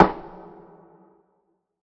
Perc [ Soul ].wav